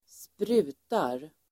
Uttal: [²spr'u:tar]
sprutar.mp3